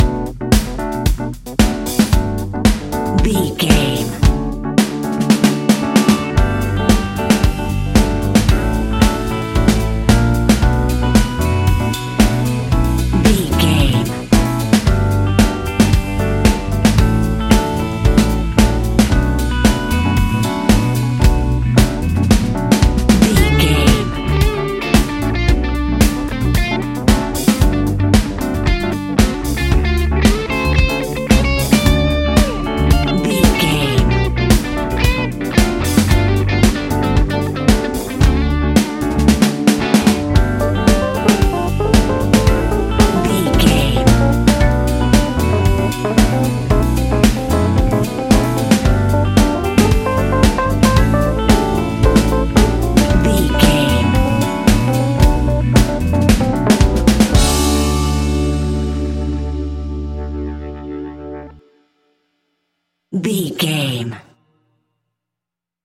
Ionian/Major
D♭
house
electro dance
synths
techno
trance